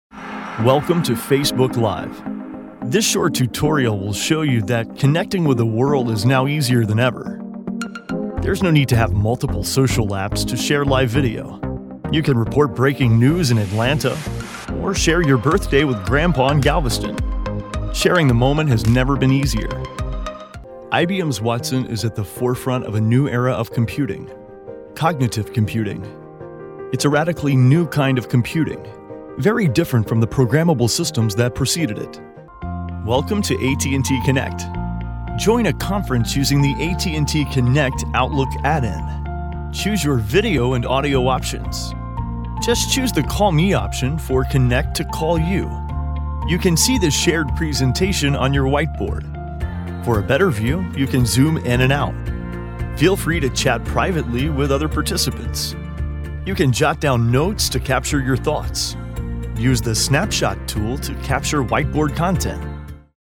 Male
Yng Adult (18-29), Adult (30-50)
★★★ EXPERIENCED PROFESSIONAL ★★★ The guy next door, with a natural approachable sound.
Explainer Videos
Explainer Narration Sample